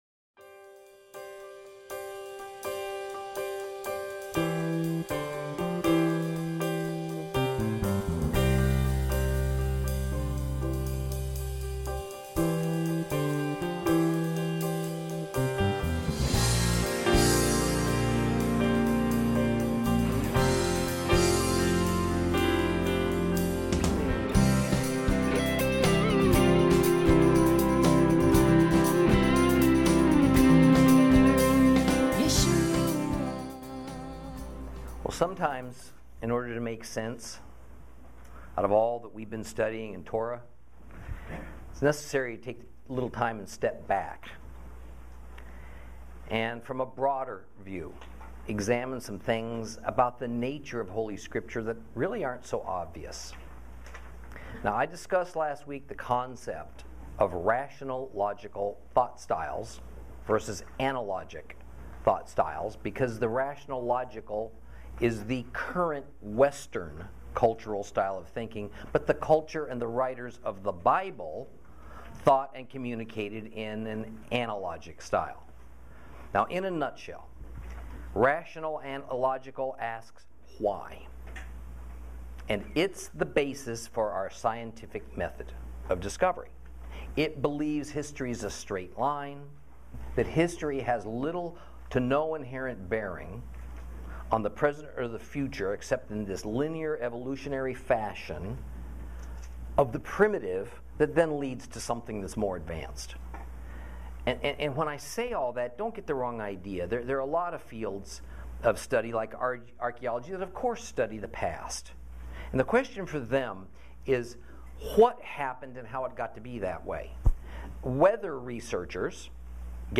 LEVITICUS Lesson 16 – Chapter 11 Continued 2